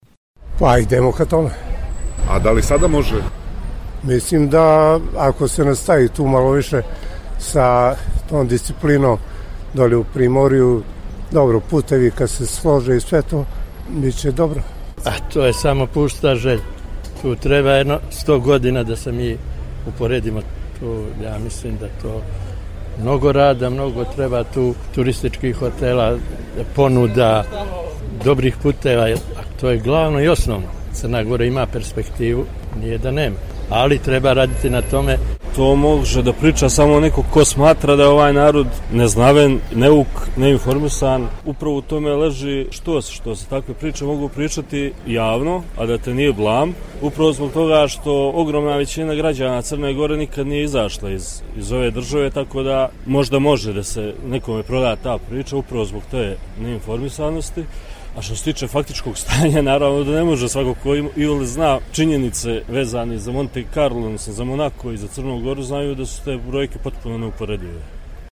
Da li se između turističkih destinacija Crne Gore i Monte Karla može staviti znak jednakosti, pitali smo i građane Podgorice.